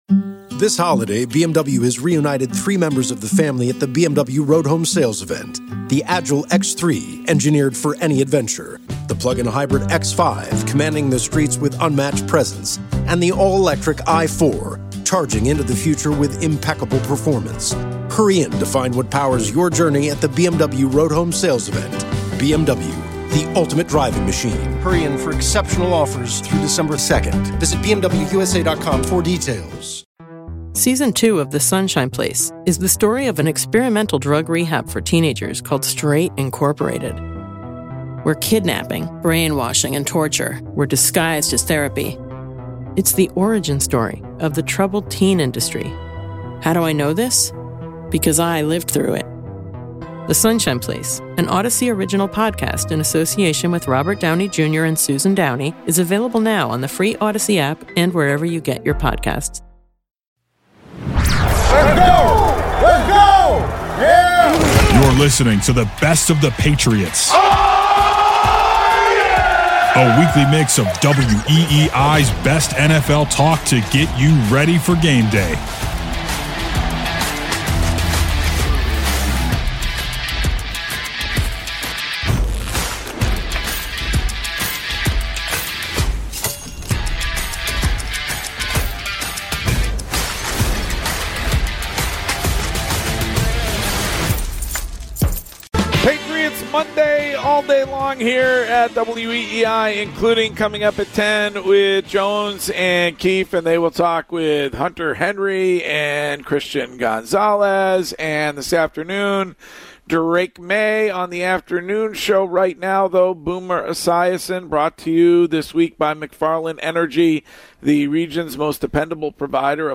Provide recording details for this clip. airs live weekdays 10 a.m. - 2 p.m. on WEEI-FM (the home of the Red Sox) in Boston and across the WEEI network in New England.